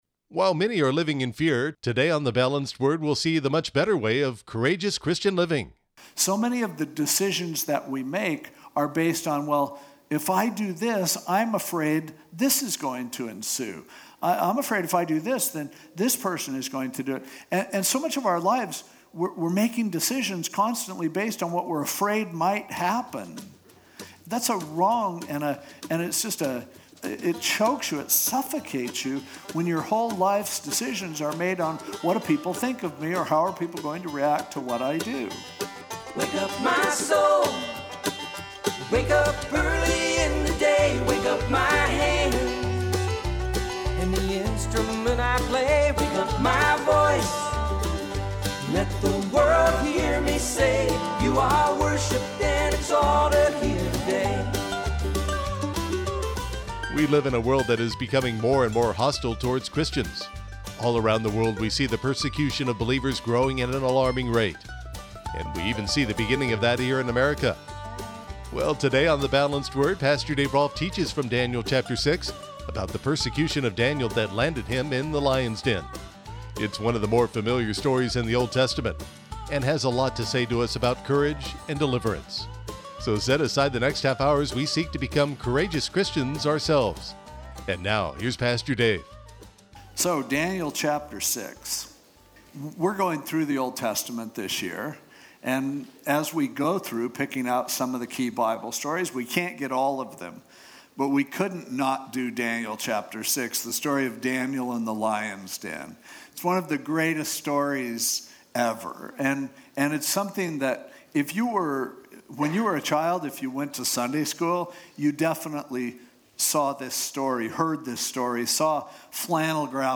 podcasted radio messages